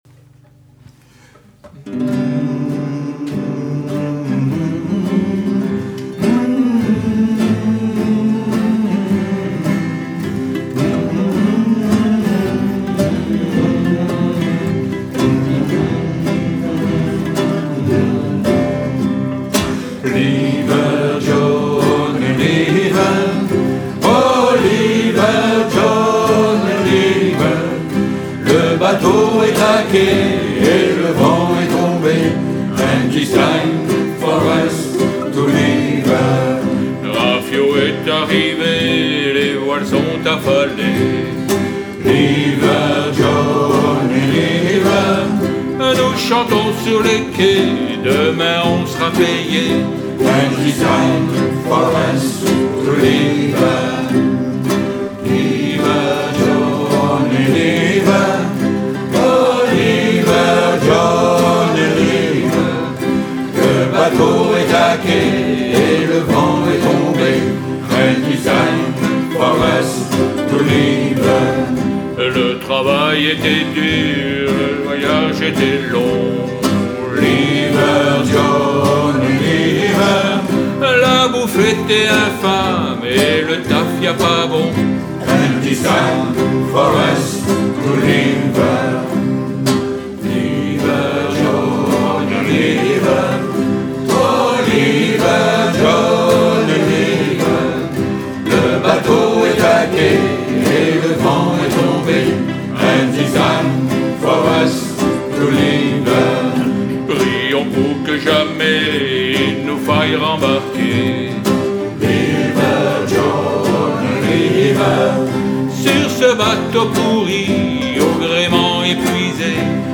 (traditionnel paroles françaises Pierre PARGUEL)